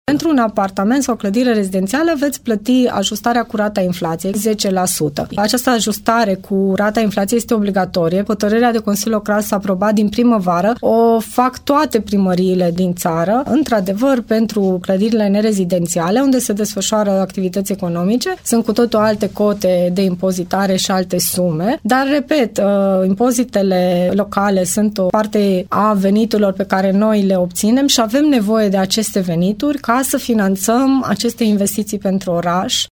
În cazul clădirilor rezidențiale, timișorenii vor plăti impozitul majorat cu rata inflației, potrivit Codului Fiscal, spune viceprimarul Paula Romocean.